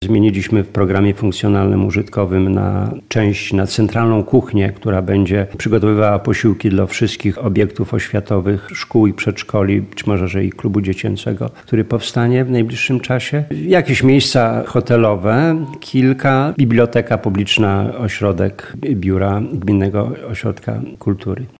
– Trwa budowa nowego budynku, w którym znajdzie się sala widowiskowa, ale obiekt będzie też wykorzystywany na różne cele – mówi zastępca wójta Gminy Terespol, Bogdan Daniluk.